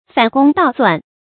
注音：ㄈㄢˇ ㄍㄨㄙ ㄉㄠˇ ㄙㄨㄢˋ
反攻倒算的讀法